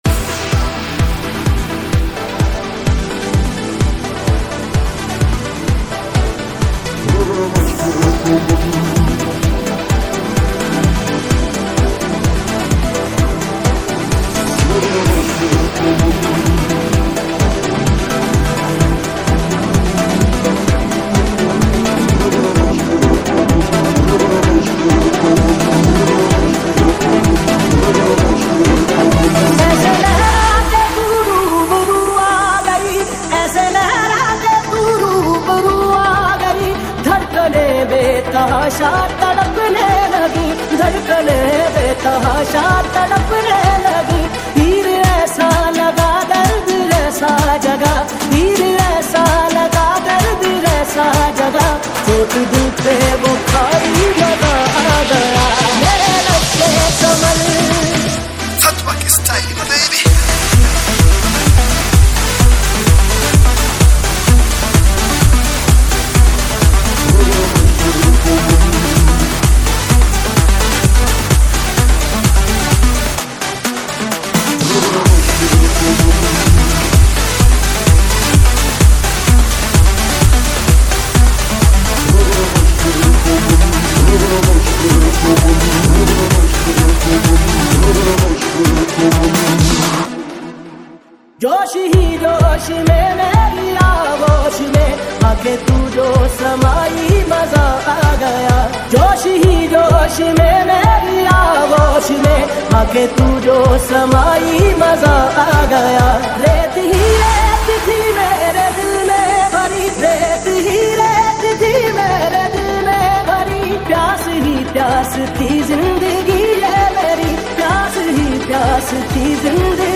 Category Hindi Dj Remix songs